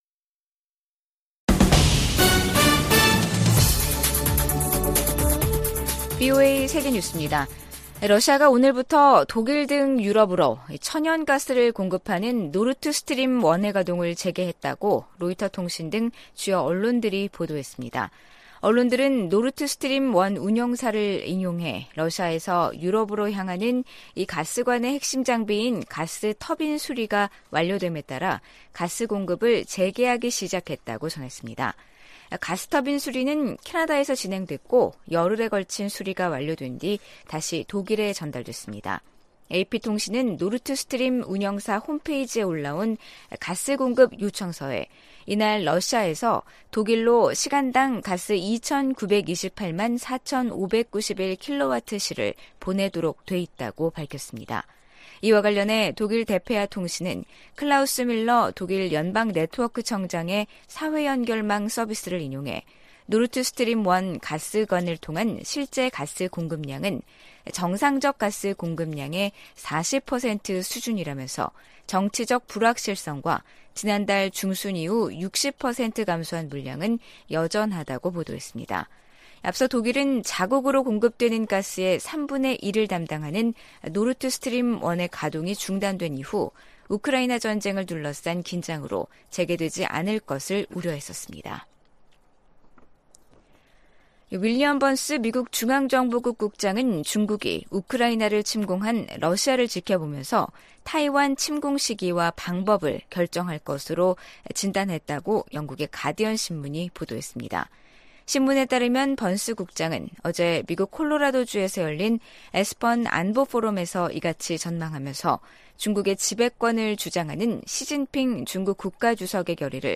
VOA 한국어 간판 뉴스 프로그램 '뉴스 투데이', 2022년 7월 21일 3부 방송입니다. 북한은 사이버 활동으로 수익을 추구하는 범죄조직이며, 이를 차단하는 것이 미국의 최우선 과제라고 백악관 고위 관리가 밝혔습니다. 백악관 국가안보회의(NSC) 측은 일본의 역내 안보 활동 확대가 북한 등 위협에 대응하는 데 큰 도움이 될 것이라고 말했습니다. 유럽연합(EU)은 북한이 우크라이나 내 친러시아 공화국들을 승인한 데 대해 국제법 위반이라고 비판했습니다.